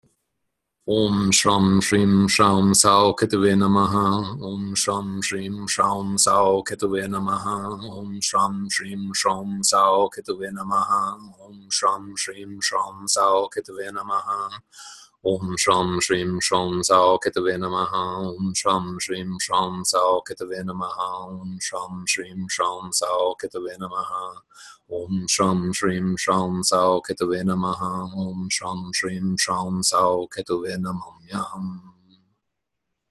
Ketu Mantra
South_Node_Ketu_Harmonizing_Mantra.m4a